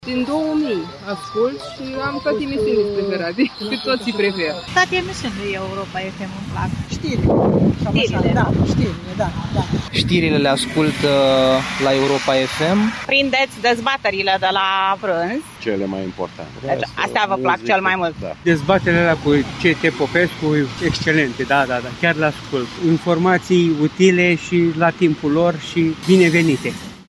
Caravana Europa FM ajunge în orașul tău pentru a sărbători împreună 25 de ani de când suntem pe aceeași frecvență. Astăzi a oprit în Piatra Neamț
De 25 de ani pe aceeași frecvență – Caravana Europa FM a ajuns astăzi în Piatra Neamț.